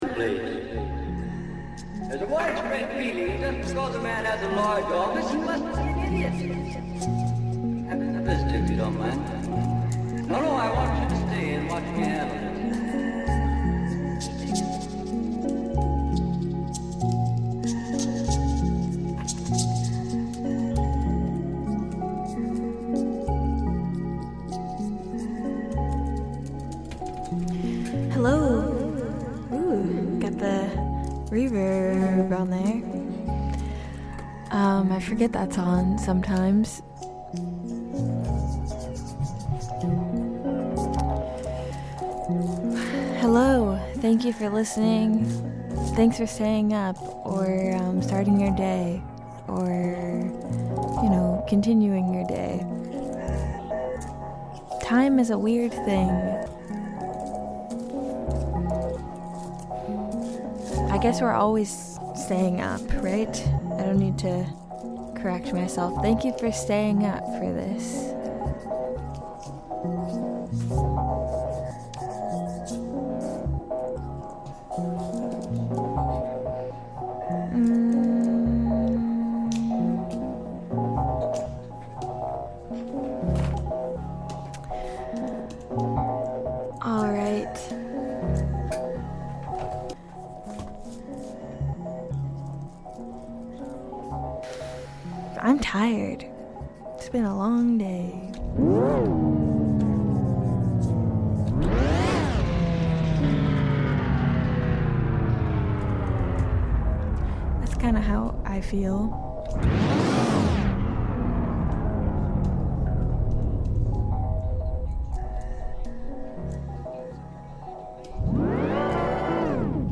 A Double Indemnity themed set, inspired by deceit, romance, Los Angeles, and other things that perhaps also began as a "good idea." In a stew of intimate electronics, bad poetry, and tender murk, we swap tongues and reach for more.